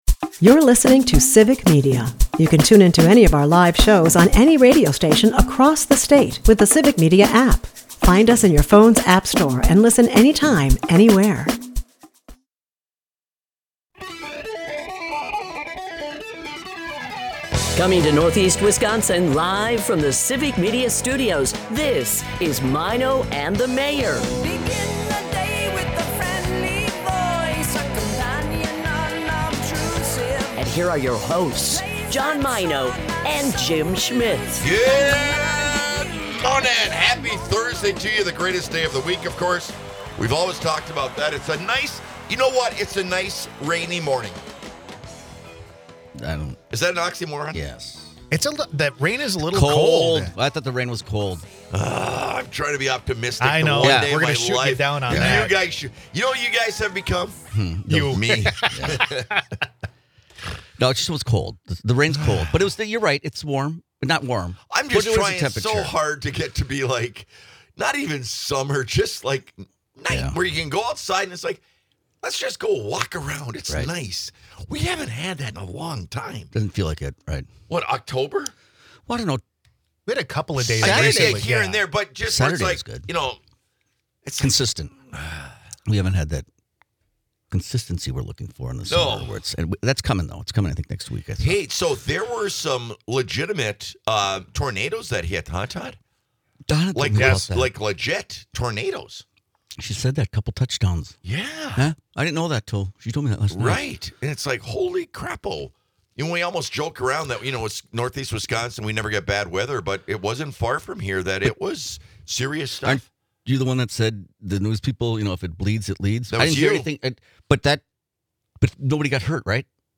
Amidst playful banter, they discuss Brett Favre's recent controversies and a potential guest spot on the show. Listeners weigh in on whether Brett Favre should be a guest, with an overwhelming yes.
Maino and the Mayor is a part of the Civic Media radio network and airs Monday through Friday from 6-9 am on WGBW in Green Bay and on WISS in Appleton/Oshkosh.